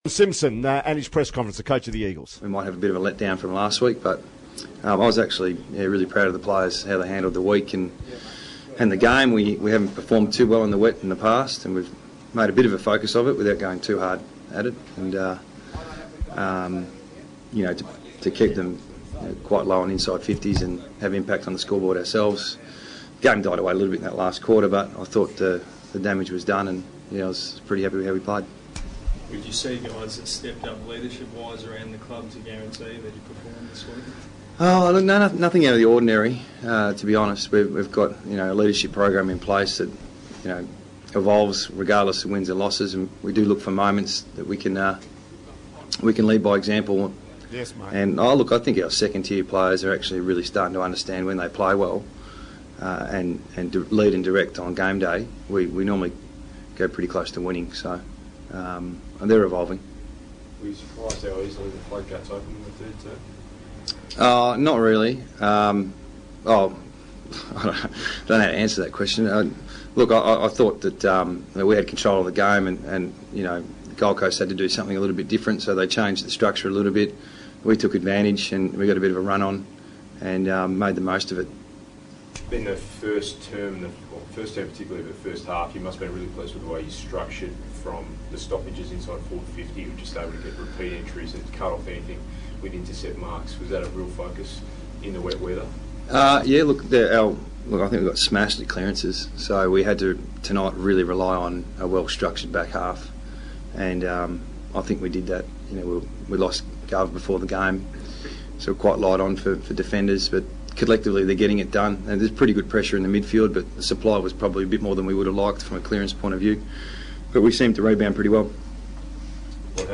Adam Simpson Post Game Press Conference